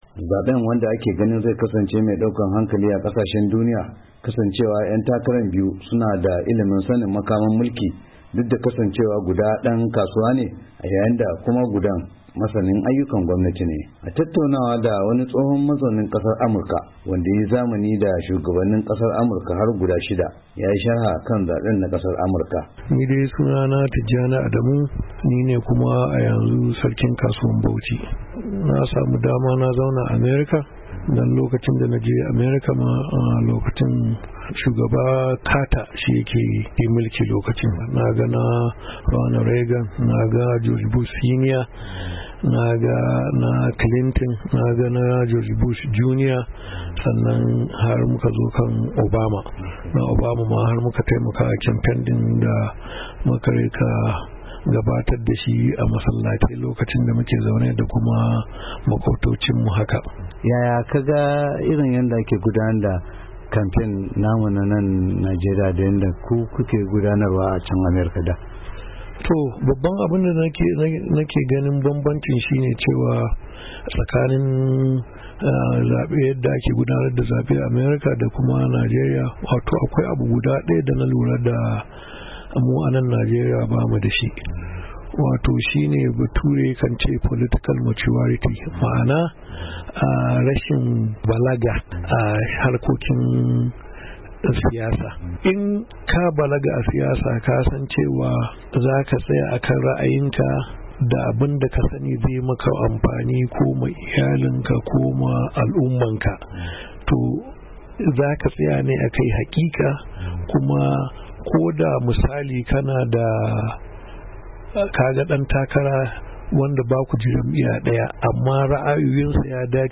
WASHINGTON, D.C —